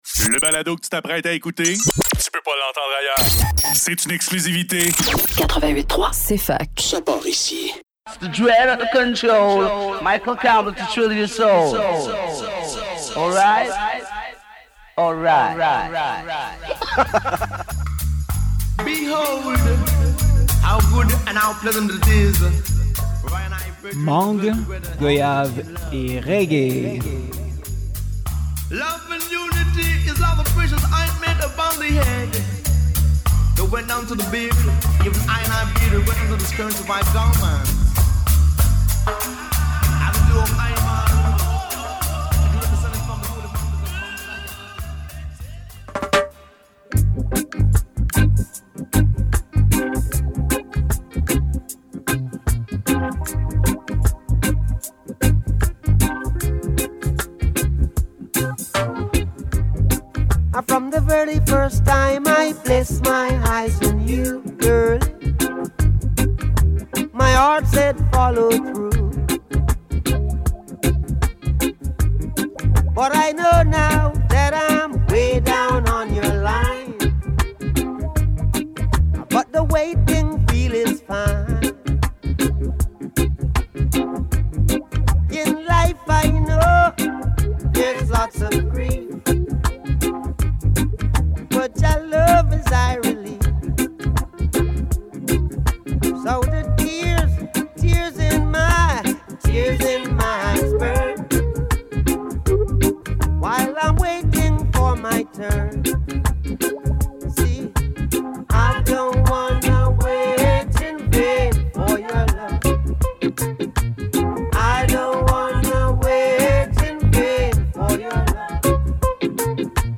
deux super versions dub